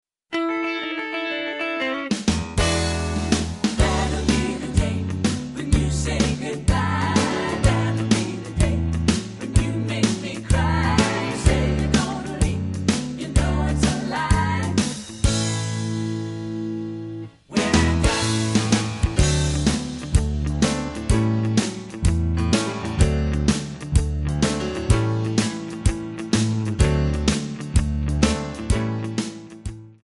Backing track files: 1950s (275)